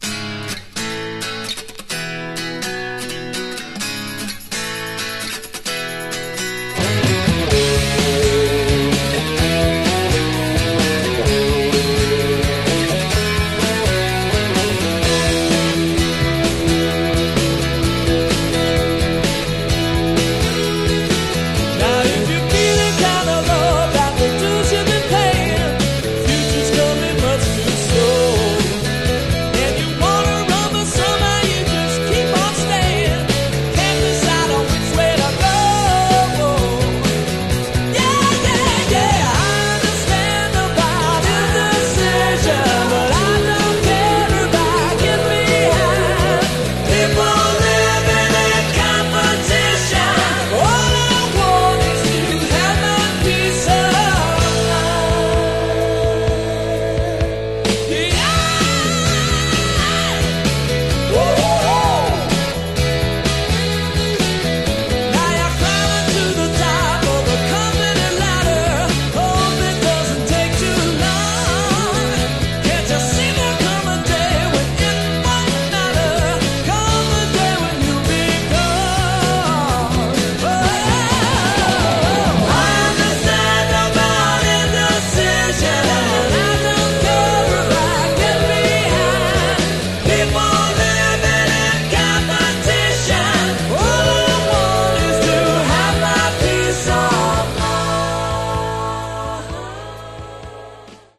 Genre: Power Pop